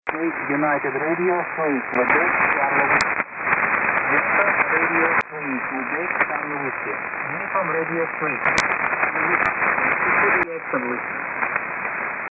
Now listen to what the Beverage does on a weaker signal.
This is reception on the Beverages.
This is reception on the dipole. I switch back and forth between the dipole and Beverages a few more times in the clip.